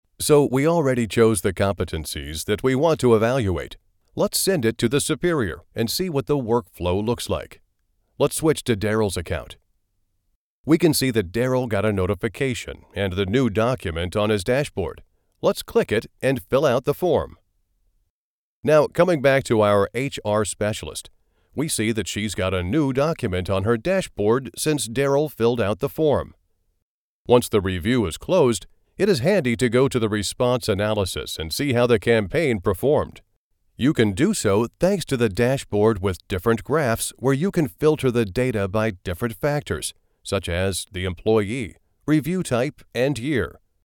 Englisch (Amerikanisch)
Kommerziell, Tief, Erwachsene, Freundlich
E-learning